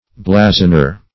Search Result for " blazoner" : The Collaborative International Dictionary of English v.0.48: Blazoner \Bla"zon*er\, n. One who gives publicity, proclaims, or blazons; esp., one who blazons coats of arms; a herald.